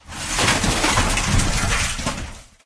scrap_into_machine_01.ogg